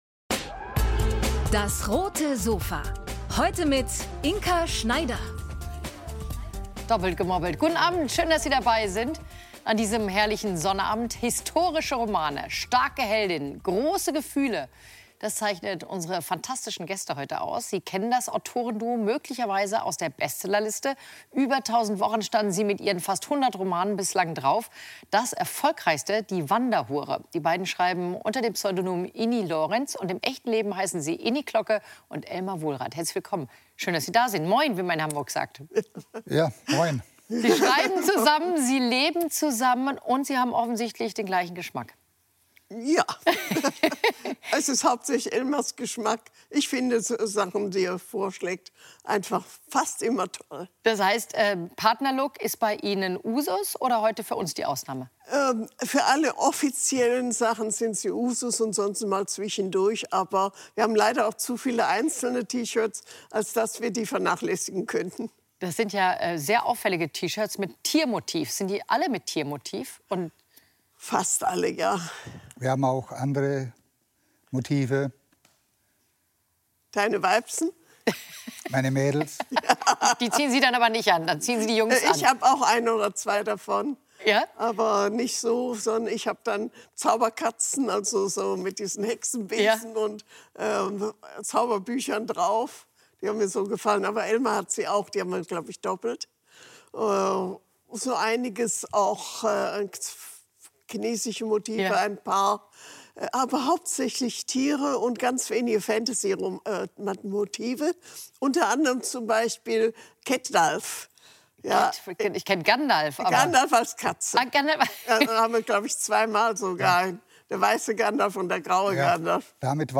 Autorenpaar Iny Klocke und Elmar Wohlrath alias "Iny Lorentz" ~ DAS! - täglich ein Interview Podcast